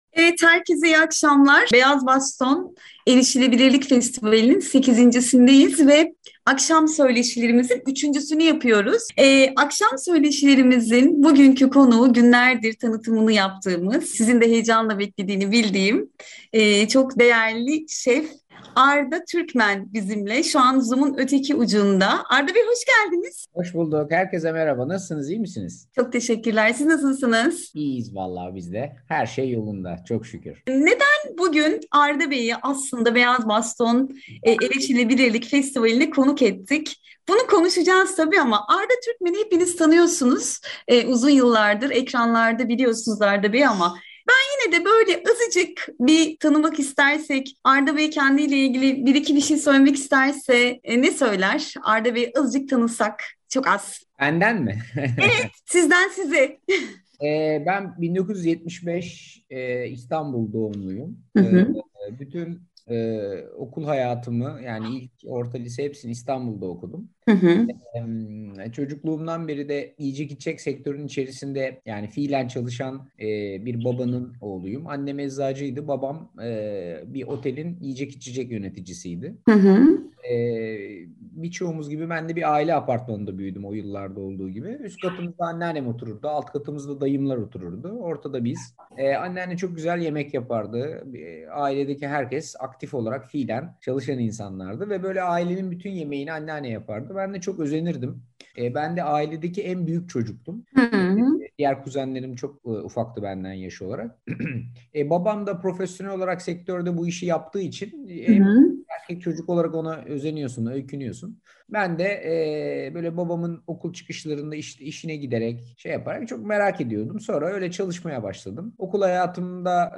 8. Beyaz Baston ve Erişilebilirlik Festivali Akşam Söyleşileri 3. Gün Etkinliği 15.10.2021 | Dünyaya Seslen
Hepinizin çok yakından tanıdığı, Ver Fırına, Arda’nın Mutfağı ve Arda ile Omuz Omuza programlarının yapımcı ve sunucusu, beslenme ve yemek uzmanı Şef Arda Türkmen festivalimizin akşam söyleşi kuşağına misafir oldu. Arda Türkmen ile spordan sağlığa, yemek ve beslenmeden sanata pek çok konuda konuştuk.